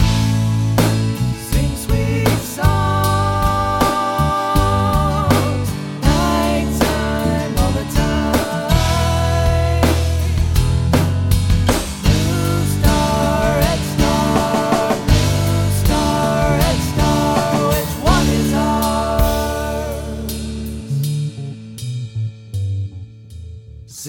I wrote this chorus for a female voice, then tried to sing it myself for the demo. The notes are a bit out of my range, and I sang it very poorly.
bsrs-nopitch.mp3